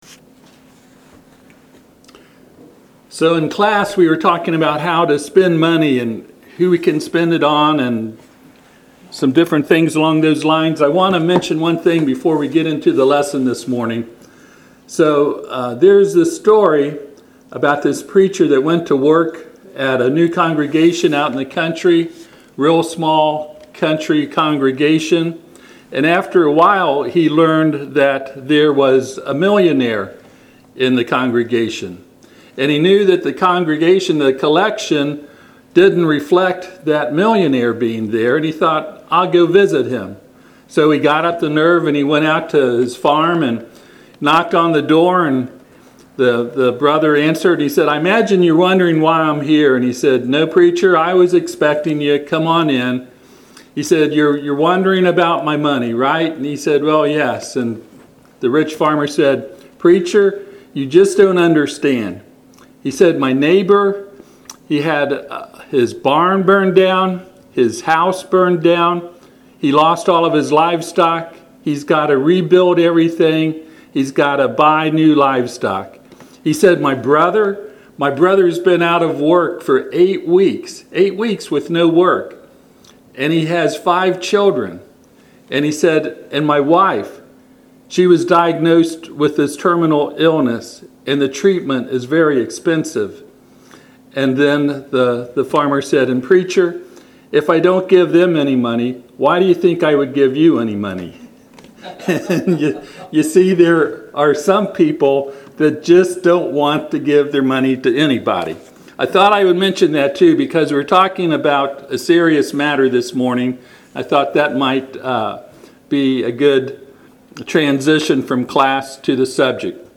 Service Type: Sunday AM